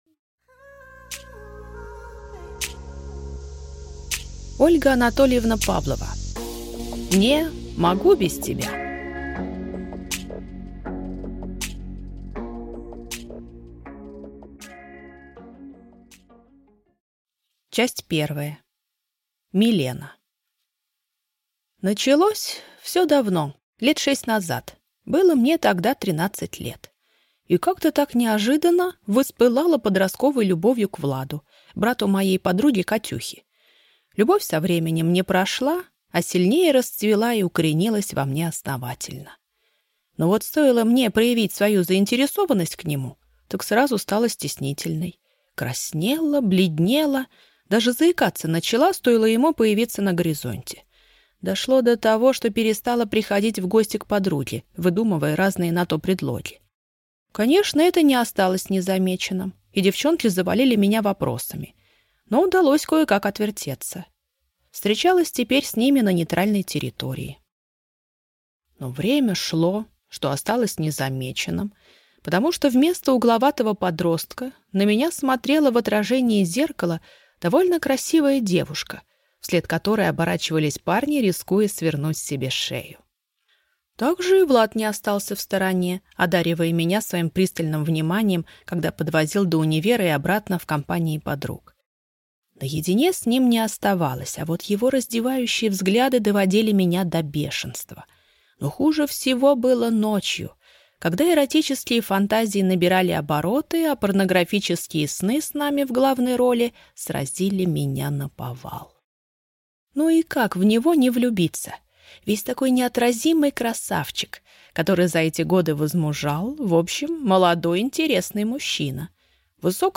Аудиокнига (Не) могу без тебя | Библиотека аудиокниг